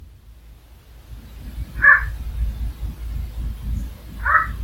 couinement dans ma charpente
j'ai enregistré ce couinement que j'entends lemme régulièrement et qui semble venir du toit.
salut avec un petit traitement audio, on entend mieux le bestiaux (que je ne connais pas)